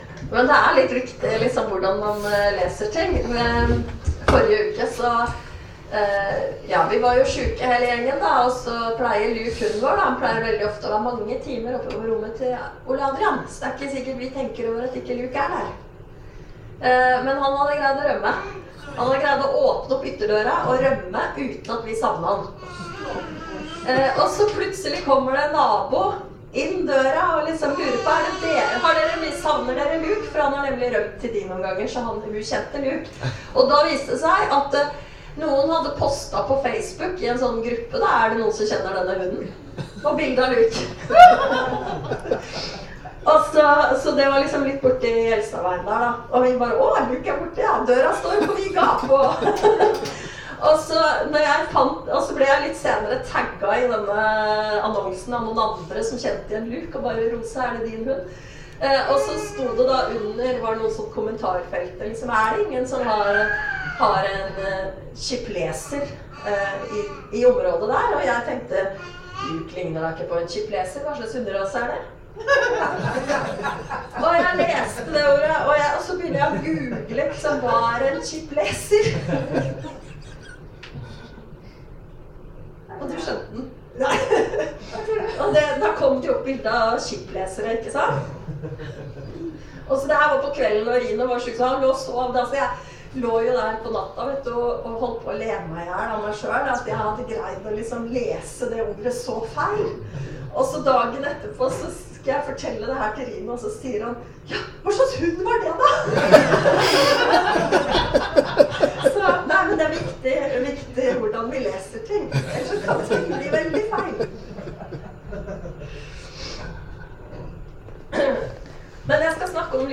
Tale i Tønsberg misjonskirke 14.05.23